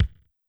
CLF Kick.wav